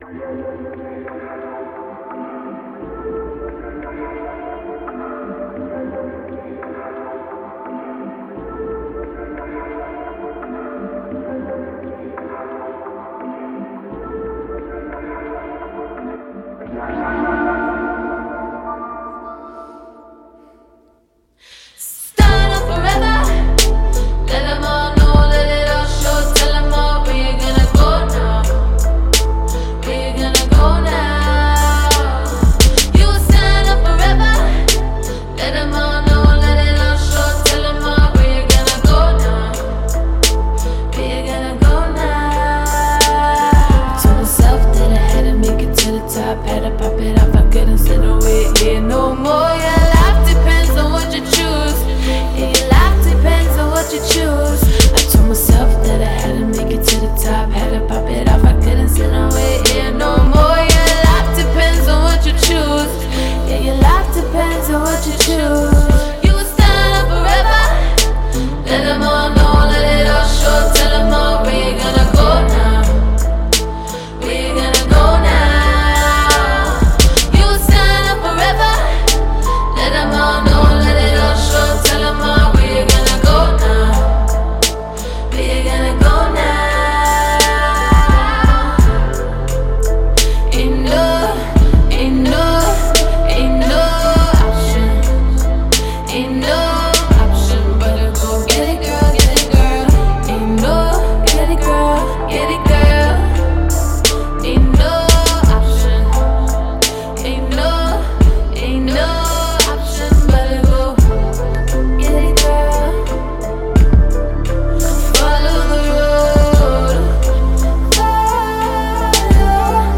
urbanR&B